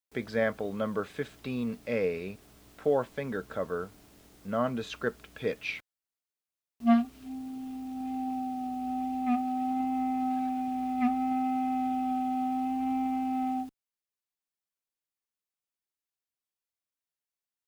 NON-DESCRIPT PITCH
EXAMPLE #15a (Lower pitches)
EXAMPLE #15b (Higher pitches)